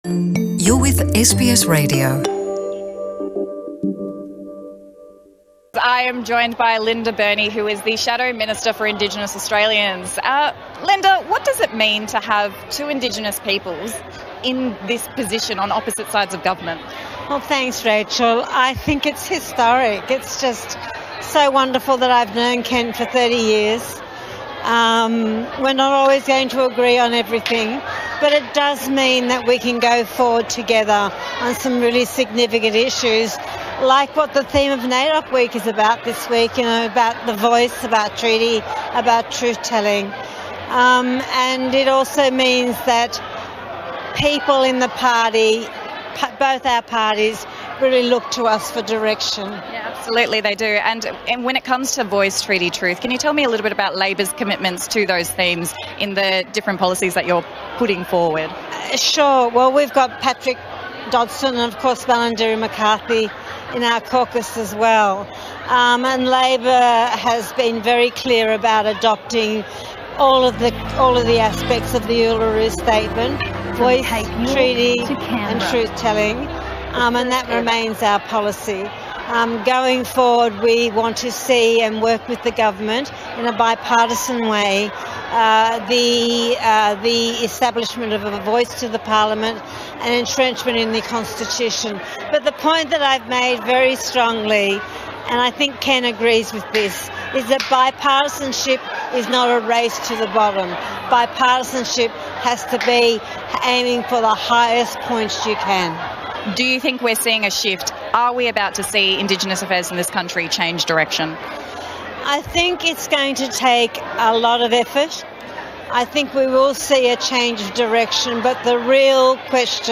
Linda Burney – Shadow Minister for Indigenous Australians at the National NAIDOC Awards Ceremony.